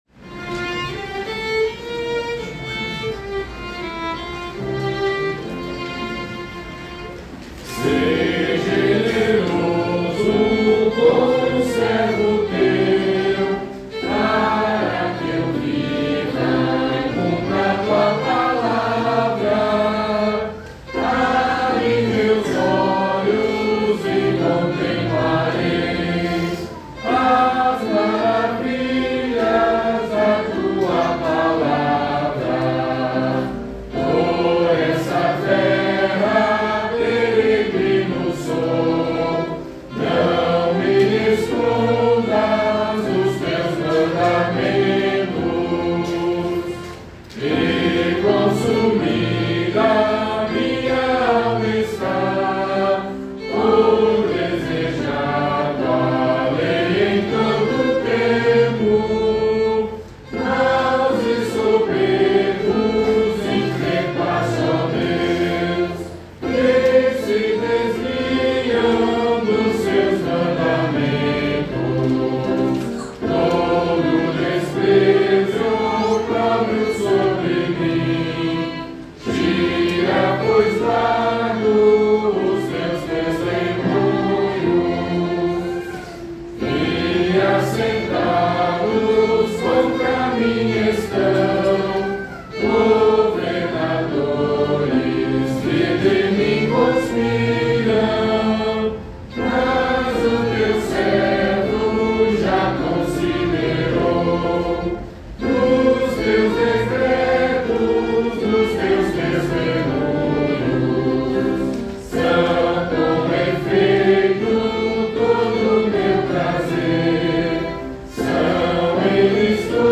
Modo: hipojônio
Downloads Áudio Áudio instrumental (MP3) Áudio intrumental (MIDI) Partitura Partitura 4 vozes (PDF) Cifra Cifra (PDF) Cifra editável (Chord Pro) Mais opções Página de downloads